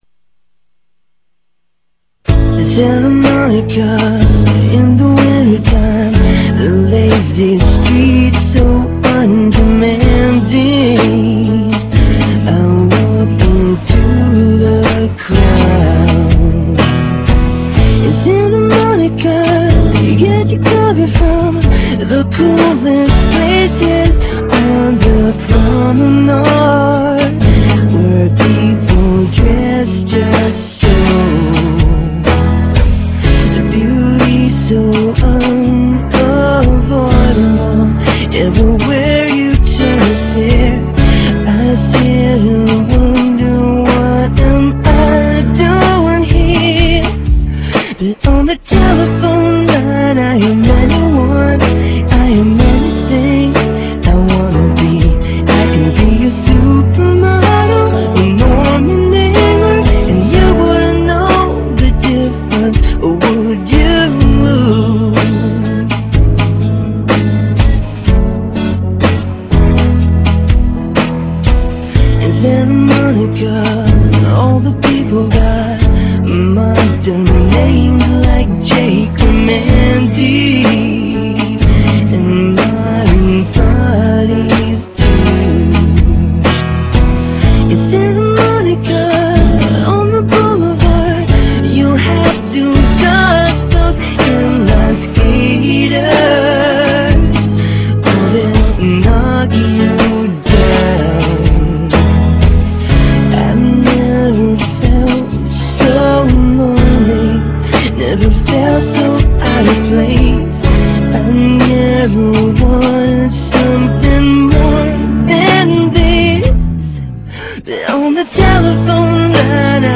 A unique song that has a nice chorus